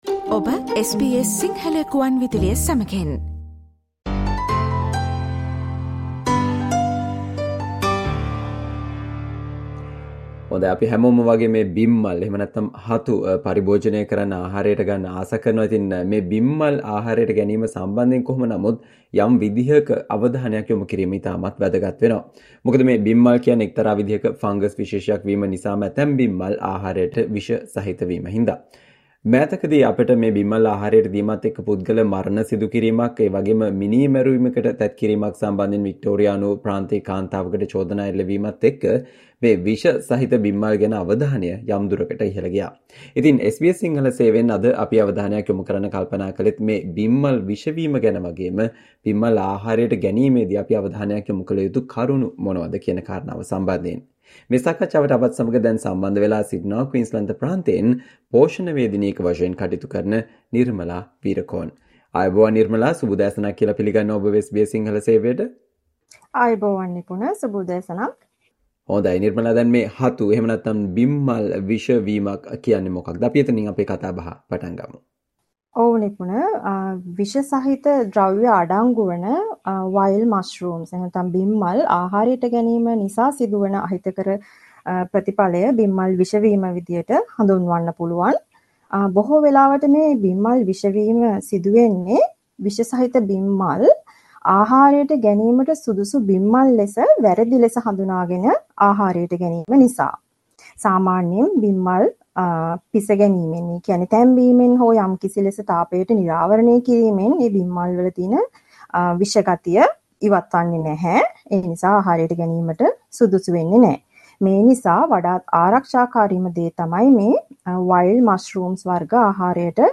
බිම්මල් හෙවත් හතු විෂ වීම පිළිබඳව සහ බිම්මල් ආහාරයට ගැනීමේදී අප අවධානයක් යොමු කල යුතු කරුණු පිළිබඳව SBS සිංහල සේවය සිදු කල සාකච්චාවට සවන්දෙන්න